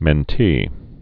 (mĕn-tē)